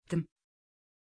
Aussprache von Tim
pronunciation-tim-tr.mp3